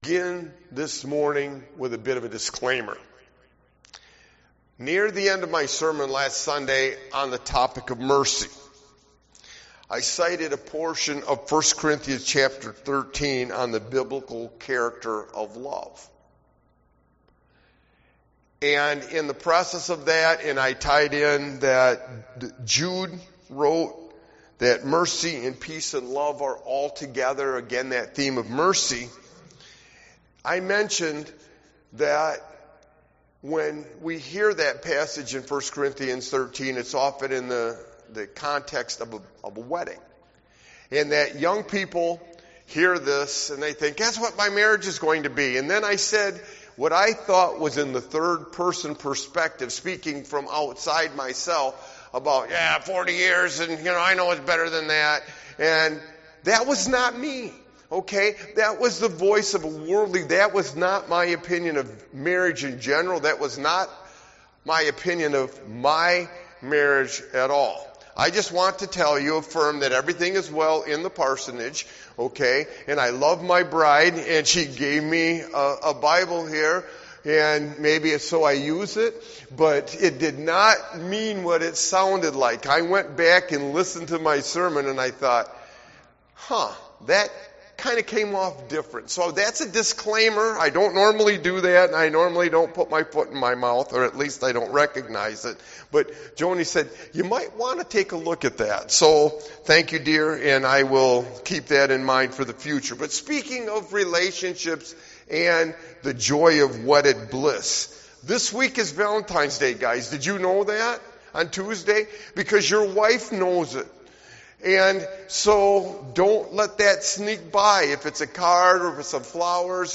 Sermon Walking with Jesus through Difficulty and Loss 6